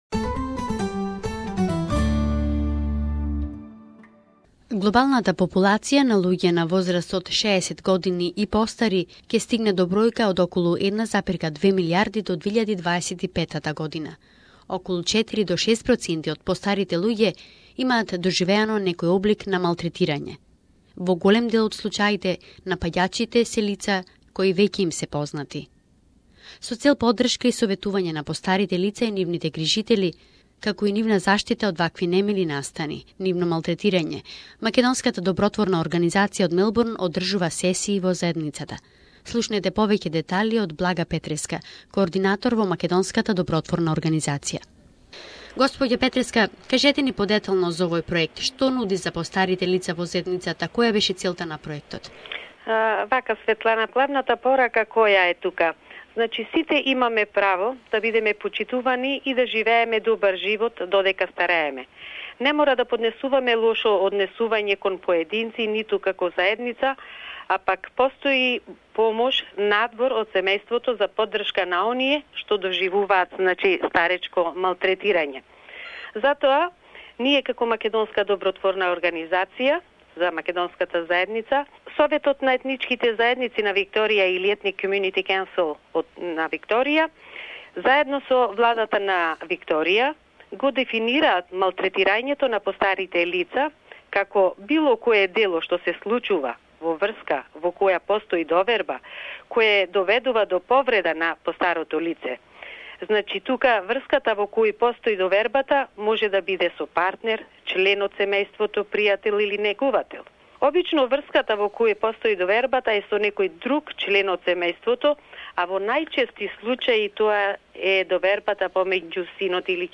Our elders are often subject to abuse of different kinds, and in many cases they are familiar people. Interview